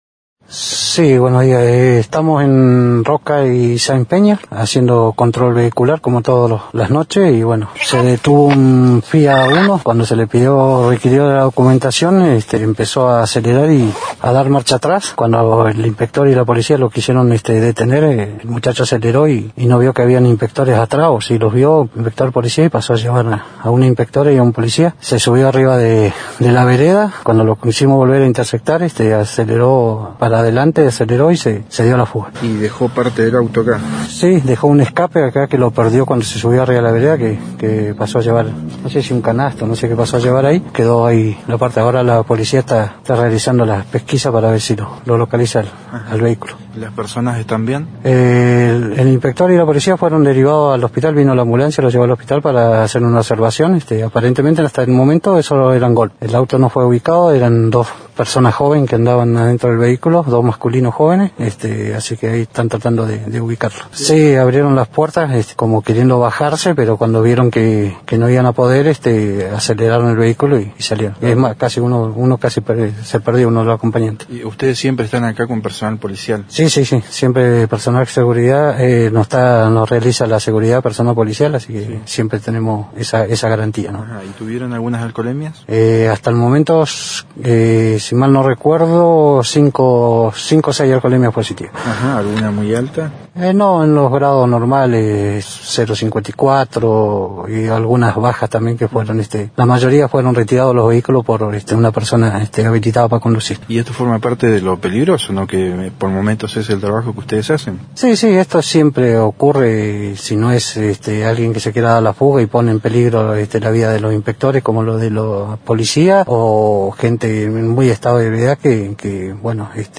Noticias de Esquel estuvo en el lugar del incidente (único medio)